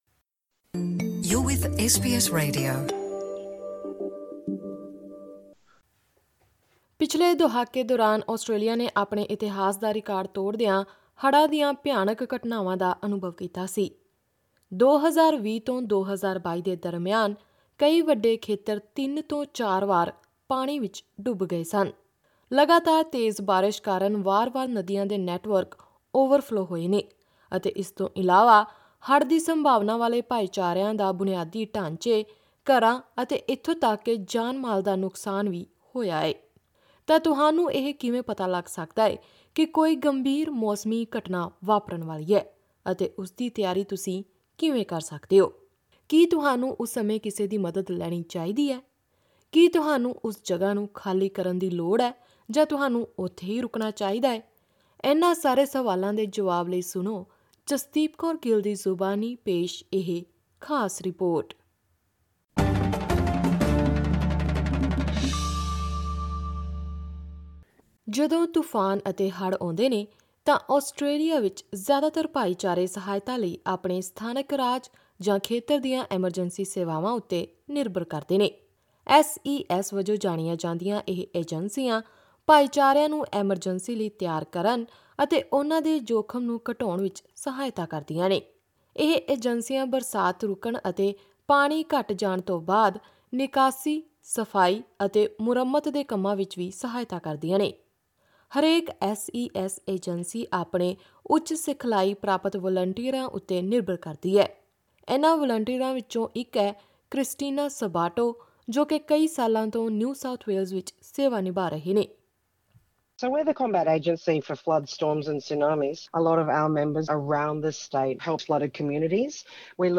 ਤੁਹਾਨੂੰ ਇਹ ਕਿਵੇਂ ਪਤਾ ਲੱਗ ਸਕਦਾ ਹੈ ਕਿ ਕੋਈ ਗੰਭੀਰ ਮੌਸਮੀ ਘਟਨਾ ਵਾਪਰਨ ਵਾਲੀ ਹੈ ਅਤੇ ਉਸਦੀ ਤਿਆਰੀ ਤੁਸੀਂ ਕਿਵੇਂ ਕਰ ਸਕਦੇ ਹੋ, ਇਹ ਜਾਨਣ ਲਈ ਸੁਣੋ ਇਹ ਖਾਸ ਰਿਪੋਰਟ।